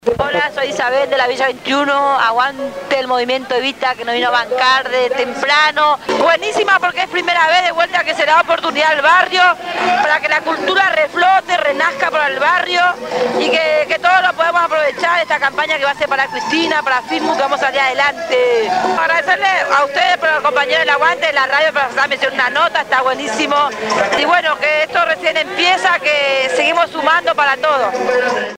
El sábado 4 de junio se llevó adelante la jornada solidaria «Somos Ambiente» en la Villa 21-24.